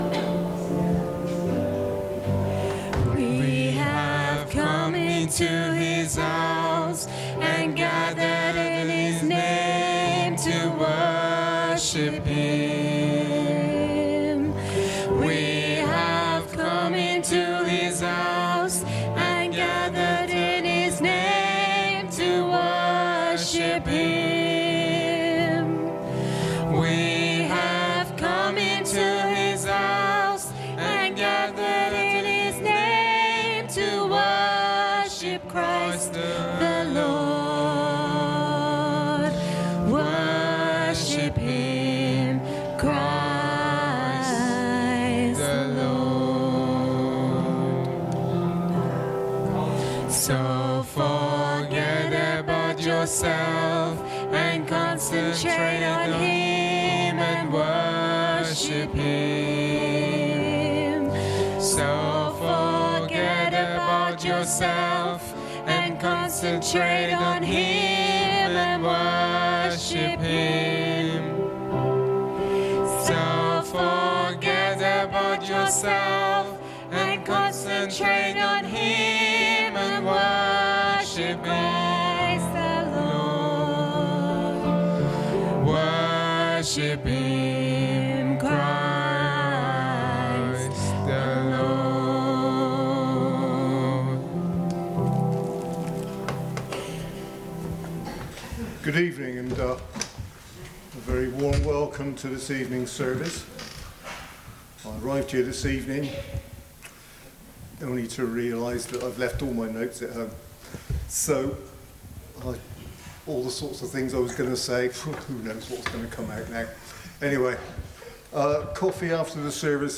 Communion service - Sittingbourne Baptist Church
Join us for our monthly evening communion service.